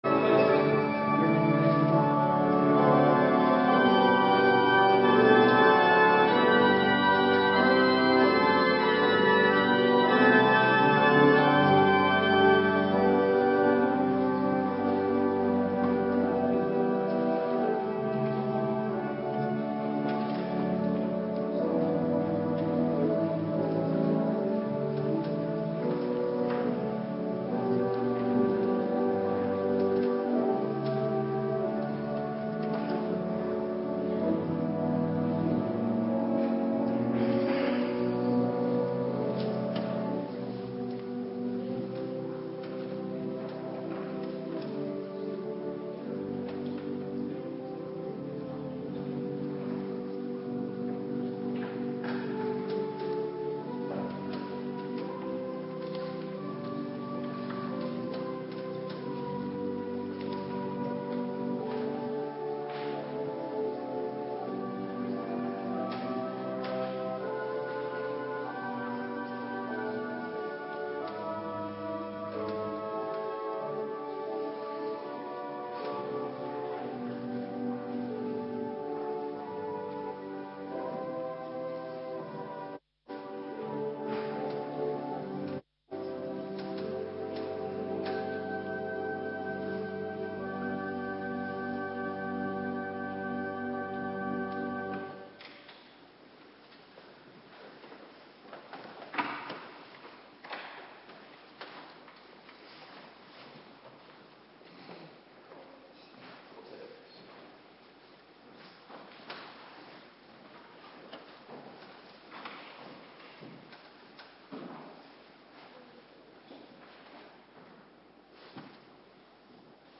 Oudejaarsdienst - Cluster 1
Locatie: Hervormde Gemeente Waarder